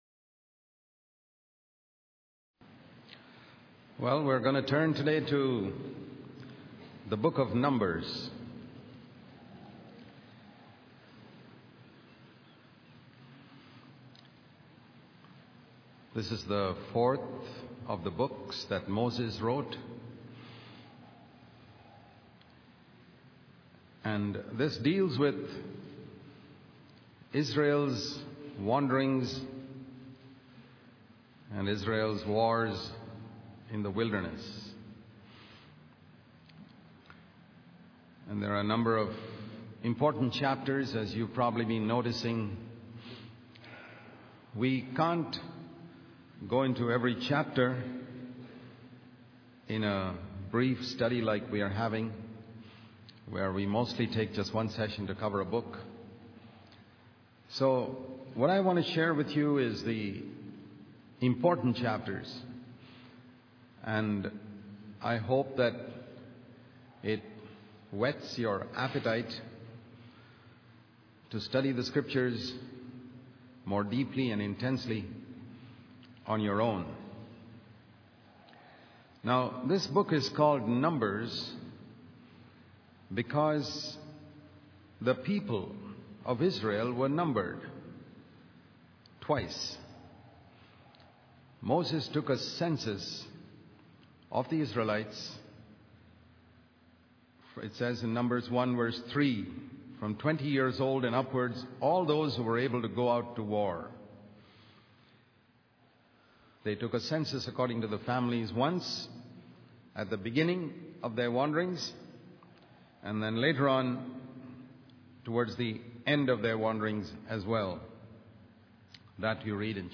In this sermon, the speaker emphasizes the importance of leaders and the higher expectations placed upon them by God. He uses the example of Moses, who longed to enter the promised land but was unable to due to his disobedience.